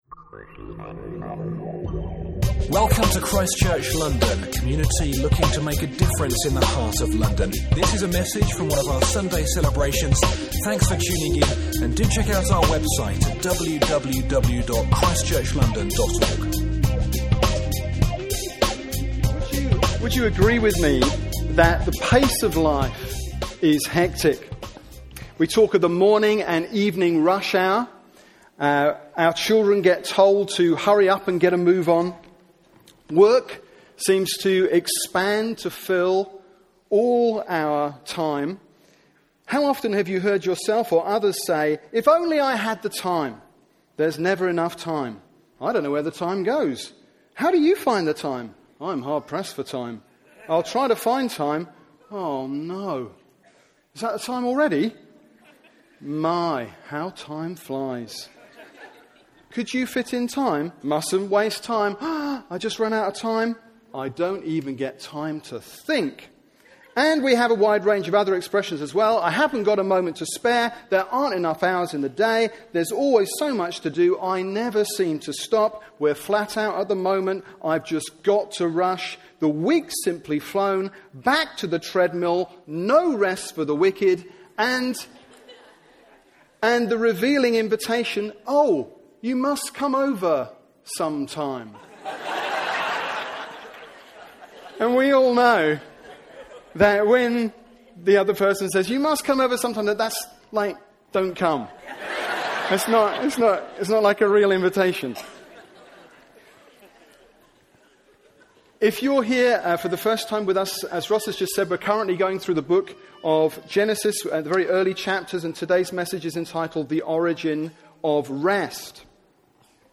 Genesis 2:1-3 – Preaching from ChristChurch London’s Sunday Service
The-Origin-of-Rest-March-2010-Sabbath-talk.mp3